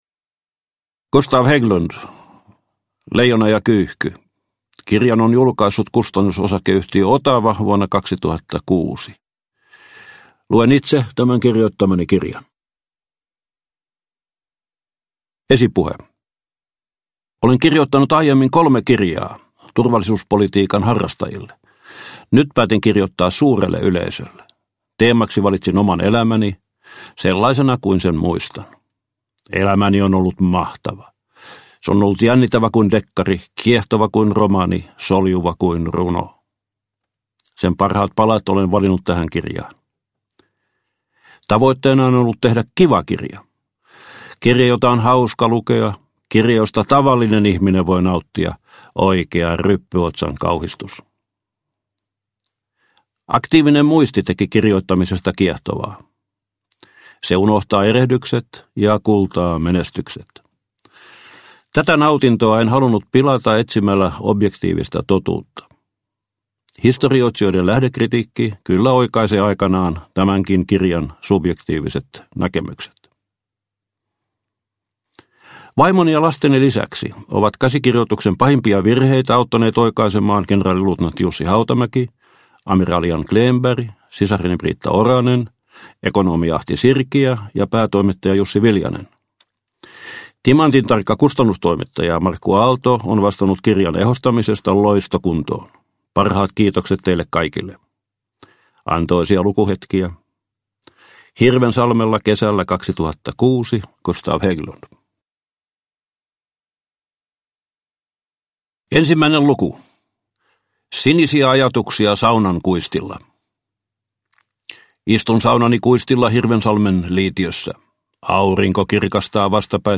Leijona ja kyyhky – Ljudbok – Laddas ner
Uppläsare: Gustav Hägglund